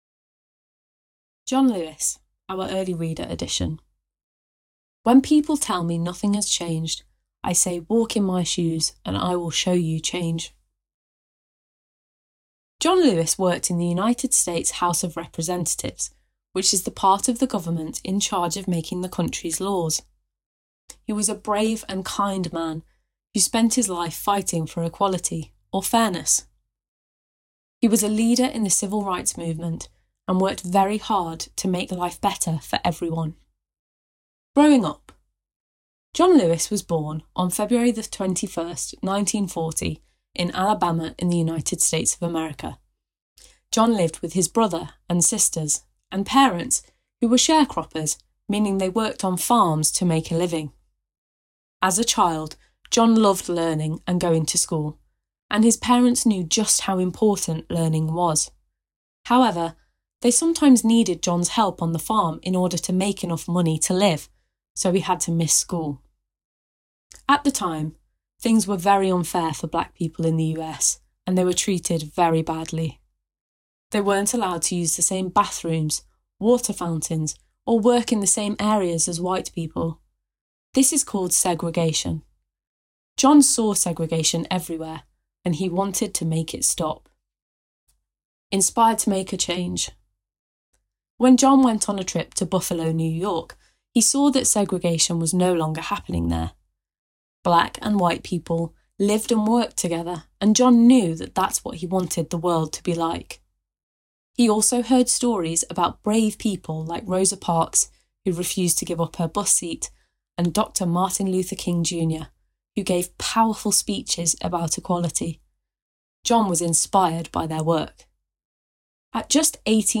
Listen to this early reader story about John Lewis, one of the great leaders in the Civil Rights Movement.